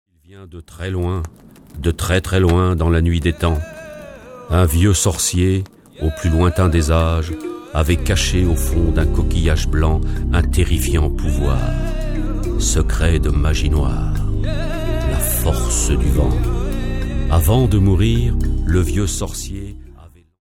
(conte)
Des chansons originales, des dialogues pris sur le vif, la voix envoutante d'un conteur, des musiques emplies d'émotion ... et, une fois n'est pas coutume, une fin aussi heureuse qu'inattendue.